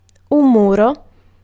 Ääntäminen
UK : IPA : /wɔːl/
US : IPA : /wɔl/